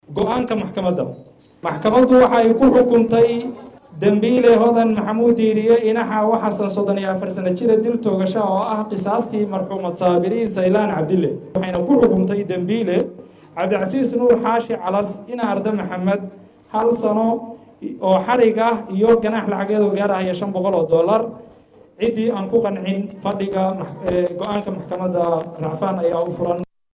Garsooraha Maxkmadda ayaa sidan ugu dhawaaqay xukunkeeda.